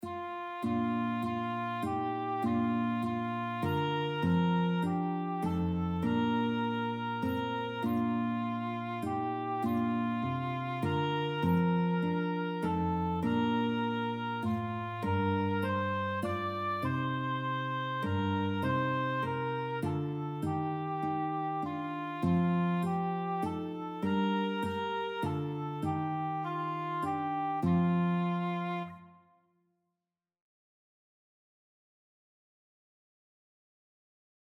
Maria die zoude naar Bethlehem gaan (1) - 1st + harp MO.mp3